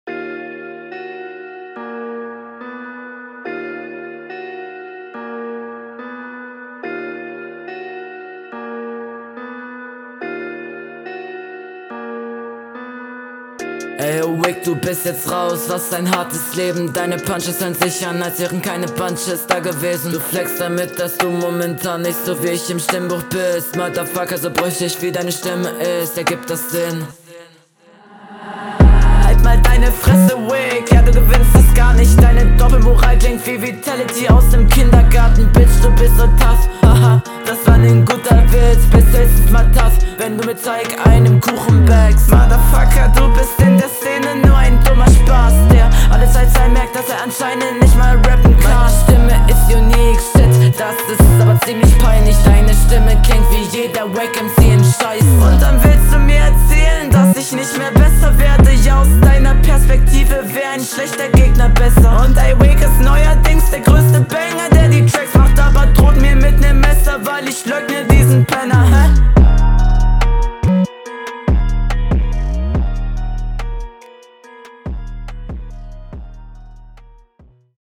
Flow: Guter Flow mit vorhandener Variation, nochmal verbessert in Betracht zur ersten Runde Text: Verstehe …